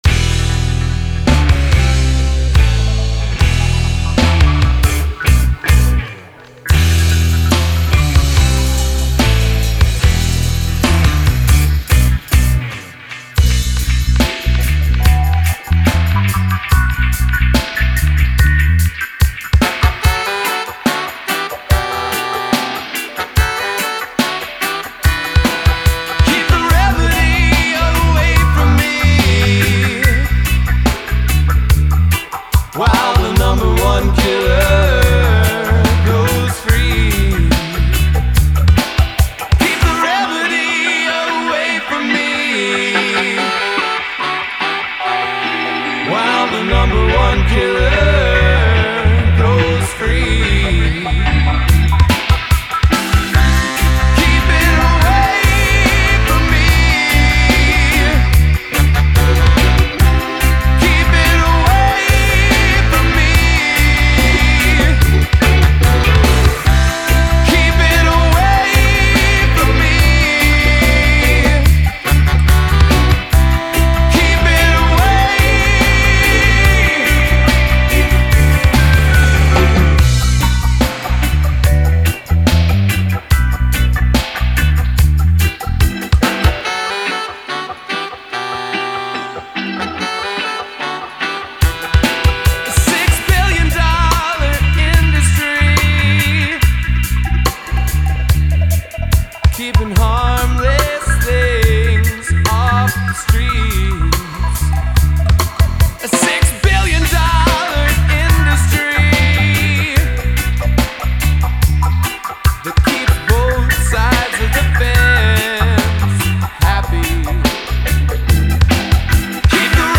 melodica
drums
bass
keys